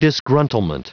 Prononciation du mot disgruntlement en anglais (fichier audio)
Prononciation du mot : disgruntlement